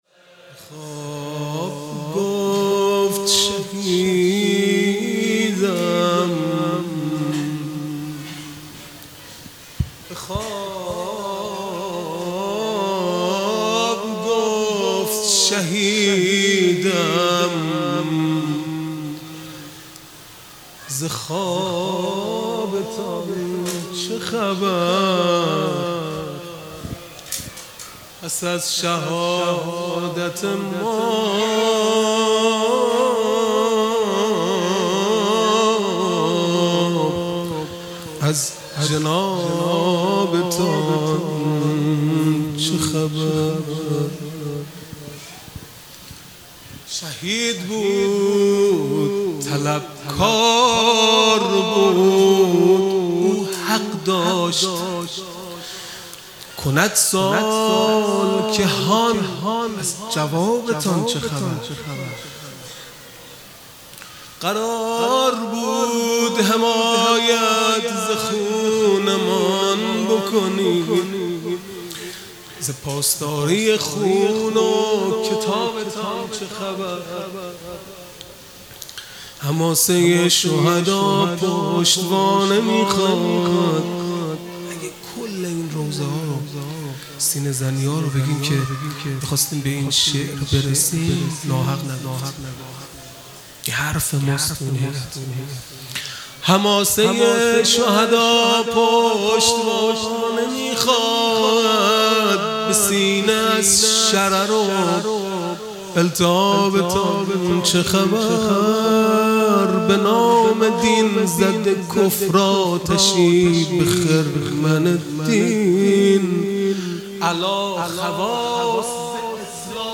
خیمه گاه - هیئت بچه های فاطمه (س) - مناجات پایانی | به خواب گفت شهیدم ز خوابتان چه خبر | ۹ مرداد ۱۴۰۱
محرم ۱۴۴۴ | شب سوم